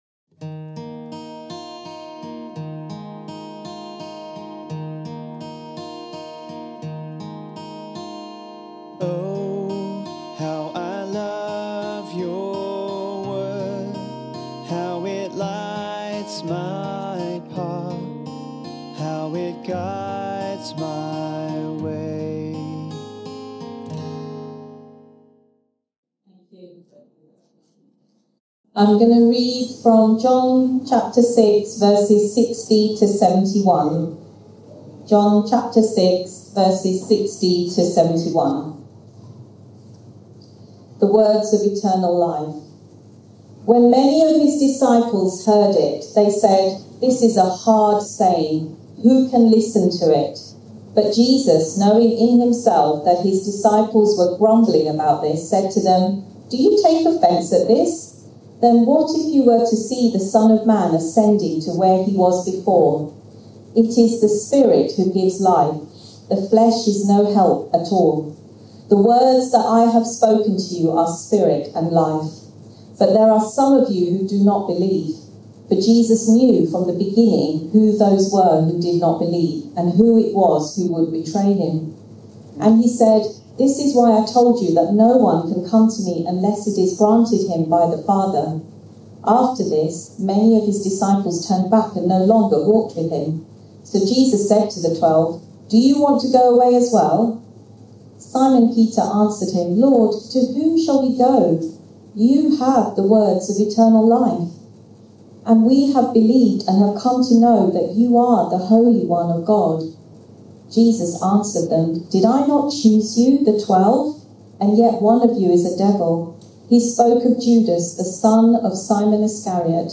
(With apologies for the poor quality of the recording)